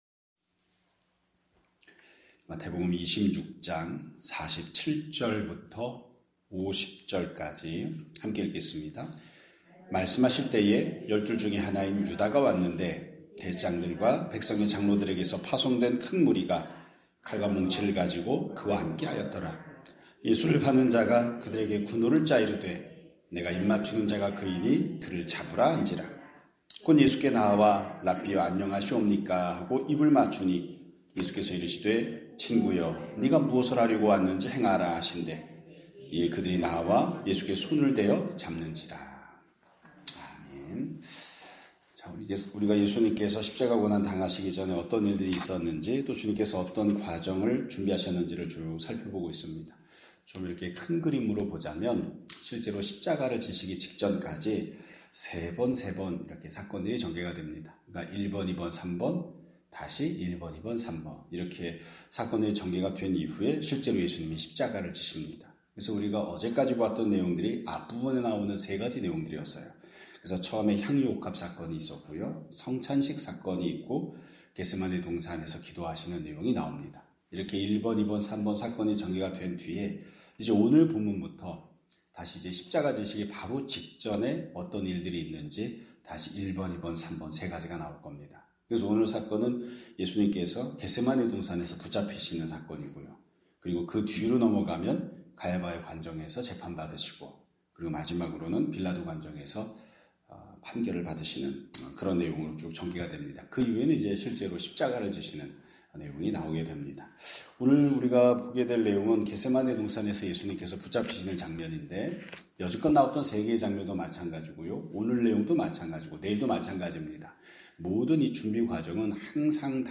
2026년 4월 7일 (화요일) <아침예배> 설교입니다.